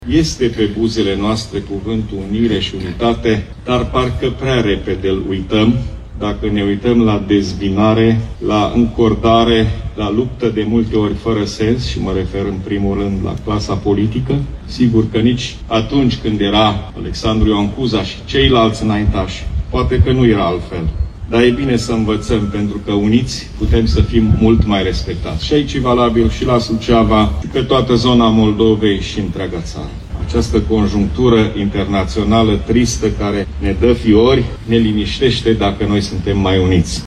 Astăzi, de Ziua Unirii Principatelor Române, oficialitățile locale și județene sucevene au inaugurat bustul lui ALEXANDRU IOAN CUZA, amplasat în Piața Drapelelor din centrul municipiului reședință.
Președintele Consiliului Județean Suceava GHEORGHE FLUTUR a chemat la solidaritate a tuturor românilor, “mai ales în aceste vremuri tulburi”.